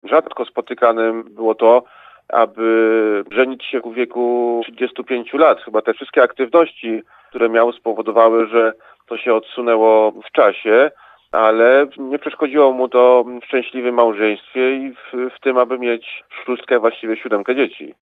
Jak dodaje dr Szpytma, aktywność zawodowa i społeczna nie przeszkodziła w budowaniu relacji rodzinnych.